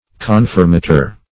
Search Result for " confirmator" : The Collaborative International Dictionary of English v.0.48: Confirmator \Con"fir*ma`tor\, n. [L.] One who, or that which, confirms; a confirmer.